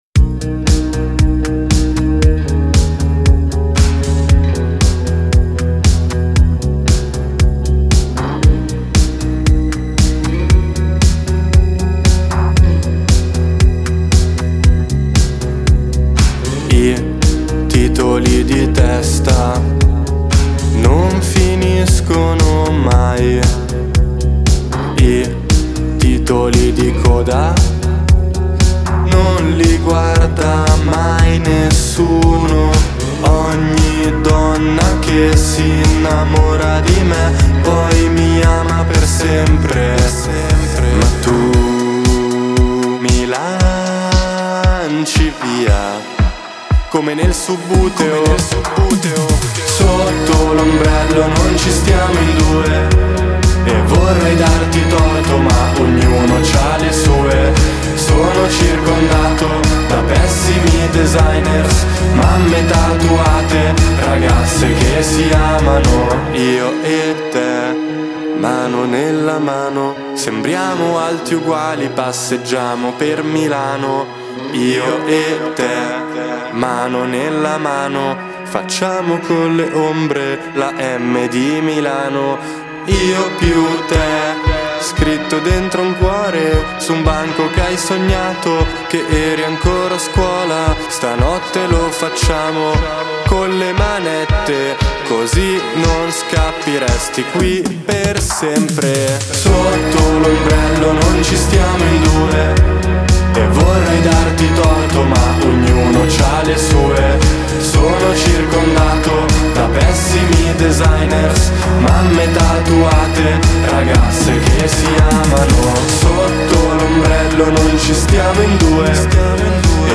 “intonato quanto basta”